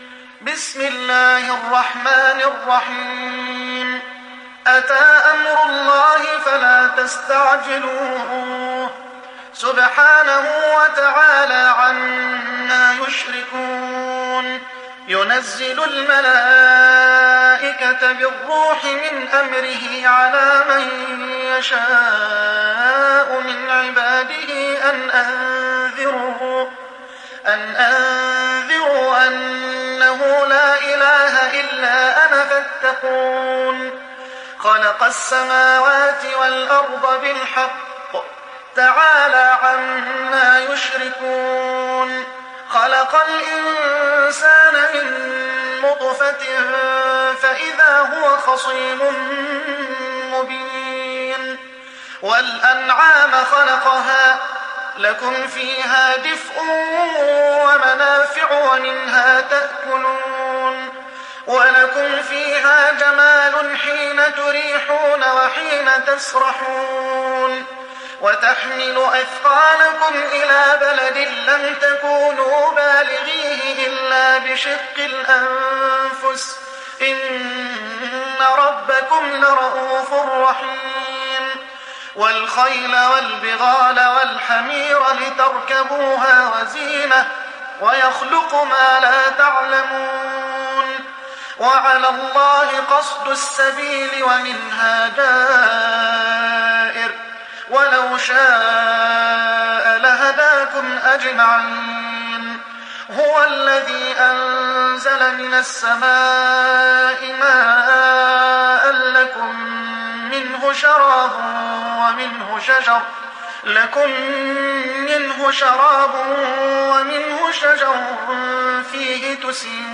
تحميل سورة النحل mp3 بصوت محمد حسان برواية حفص عن عاصم, تحميل استماع القرآن الكريم على الجوال mp3 كاملا بروابط مباشرة وسريعة